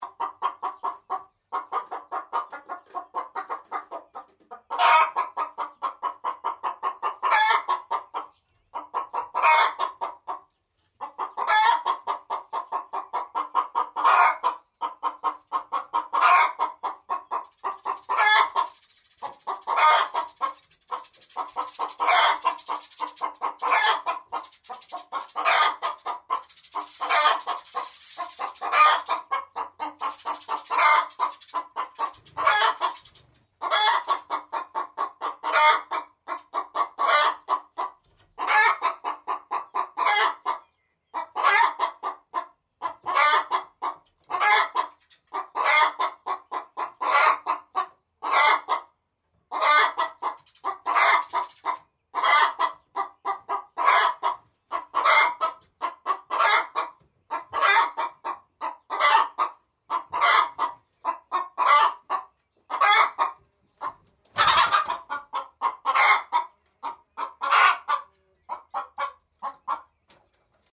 So tönt es, wenn eines unserer Hühner ein Ei gelegt hat… «brav gemacht!»
Huhn hat ein Ei gelegt